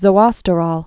(zō-ŏstə-rôl, -rōl)